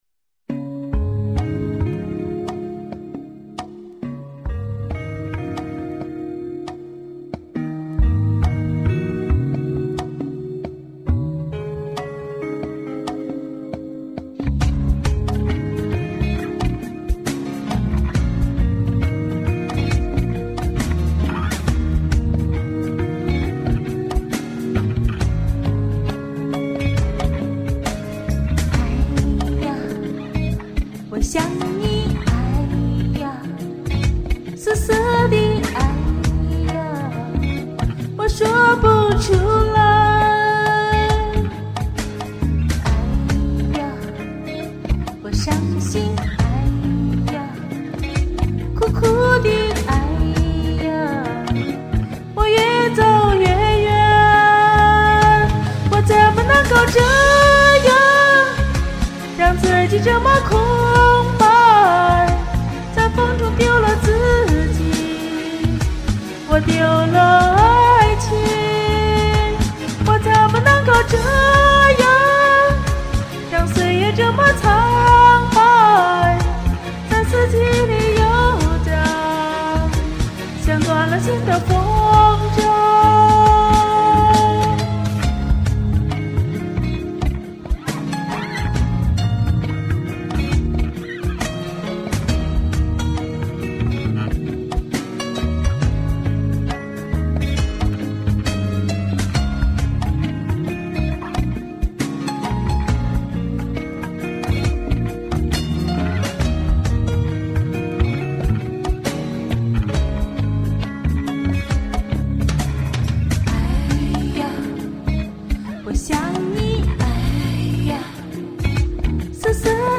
妹妹唱得真好！